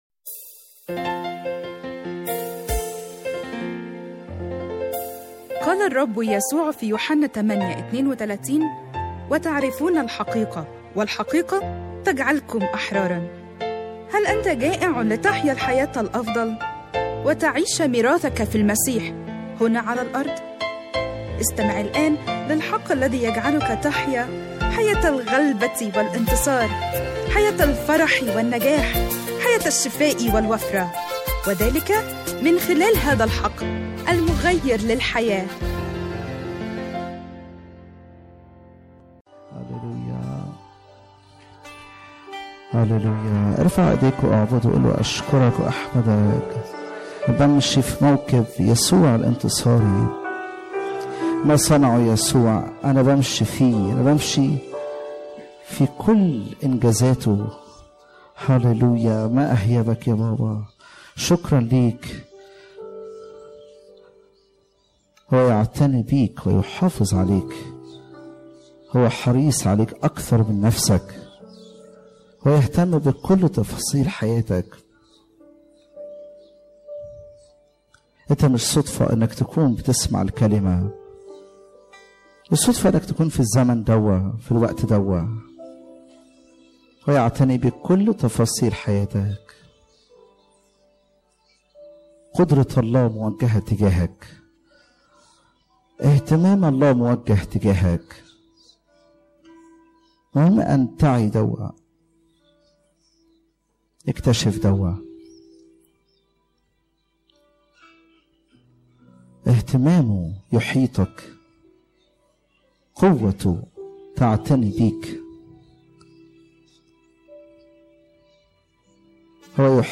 سلسلة من 4 عظات